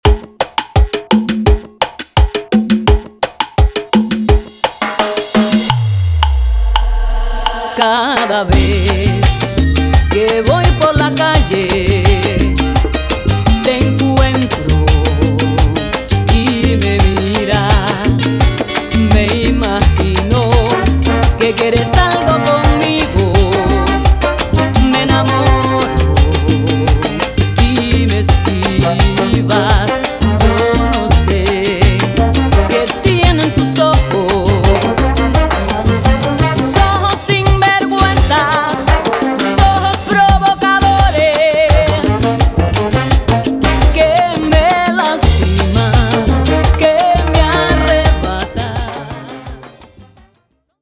salsa remix